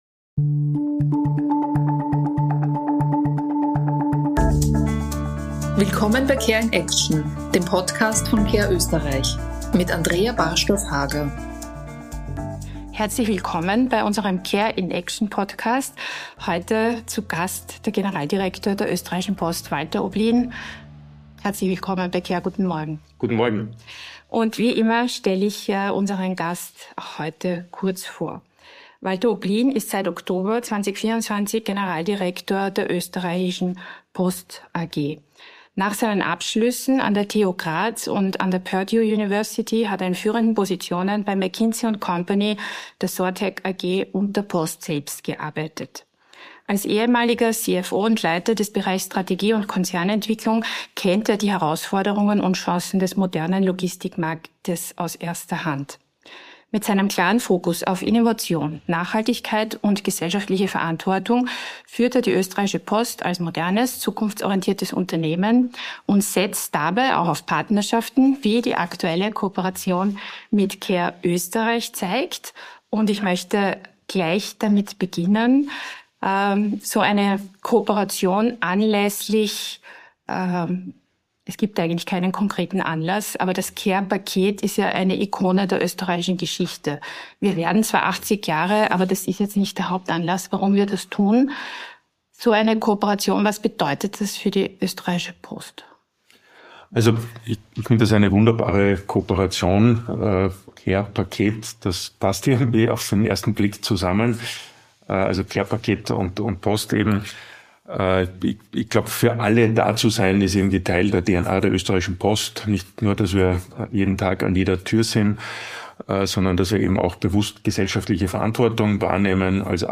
Das Gespräch wurde im Dezember 2024 anlässlich der gemeinsamen Zusammenarbeit aufgenommen.